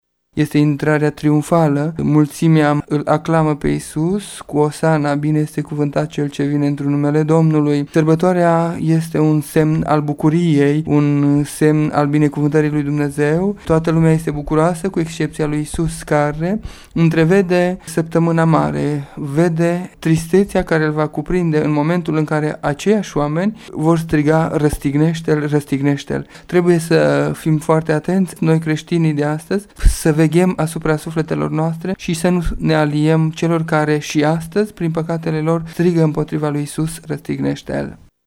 spune preotul greco-catolic din Târgu-Mureş